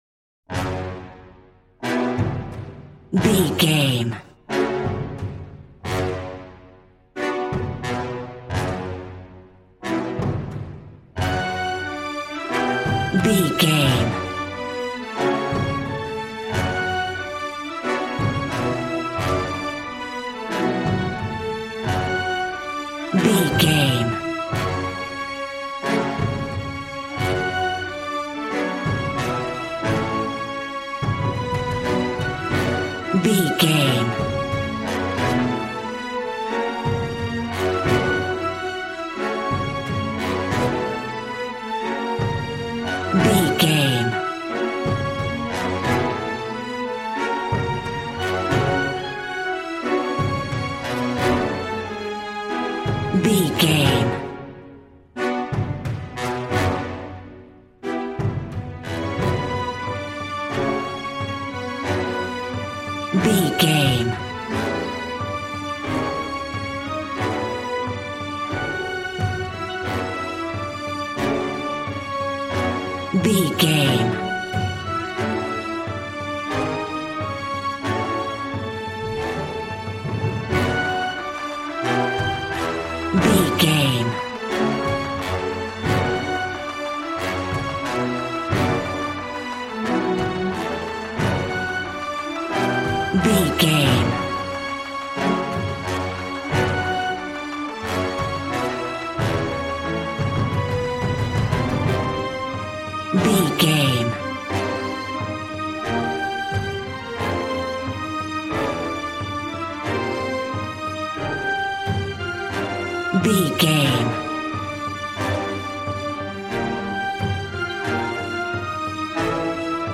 Aeolian/Minor
G♭
brass
strings
violin
regal